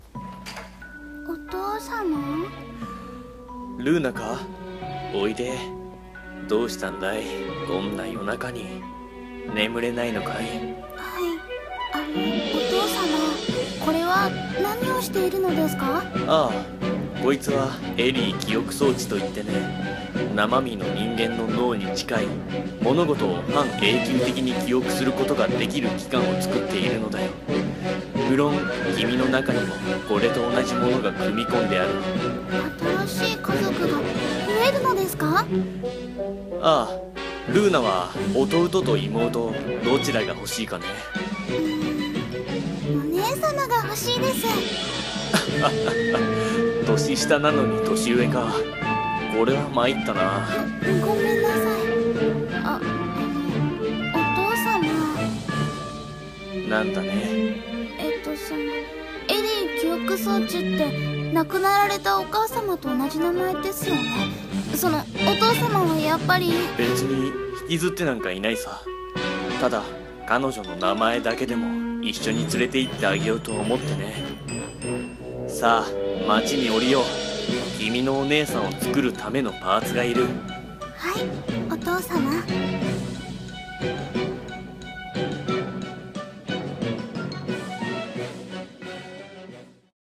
【声劇】機械仕掛けの愛娘